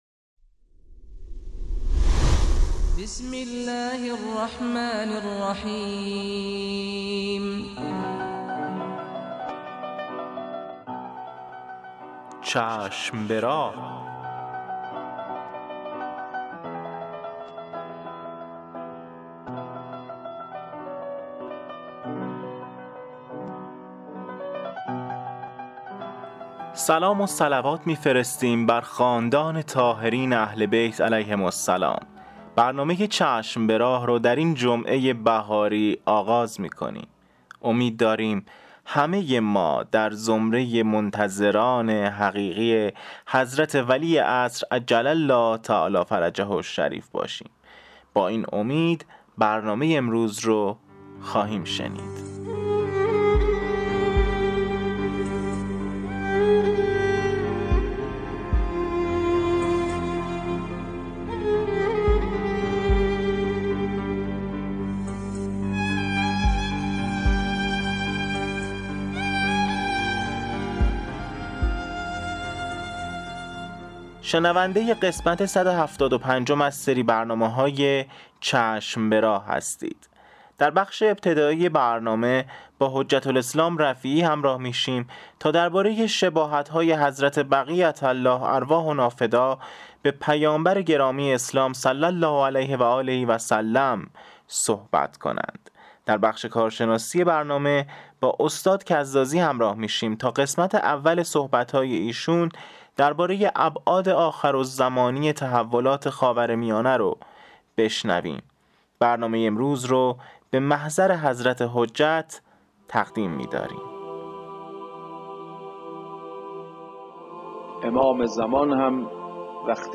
قسمت صد و هفتاد و پنجم مجله رادیویی چشم به راه که با همت روابط عمومی بنیاد فرهنگی حضرت مهدی موعود(عج) تهیه و تولید شده است، منتشر شد.